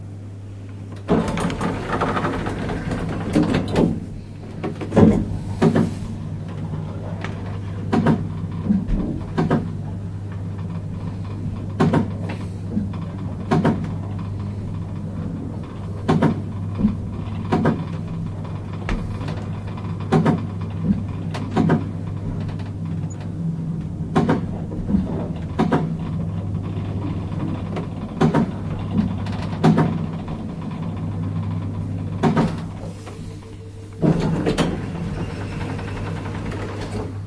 在电梯上
描述：把我带回家的电梯
Tag: 电梯 建筑 现场recordin 房子 电梯 米兰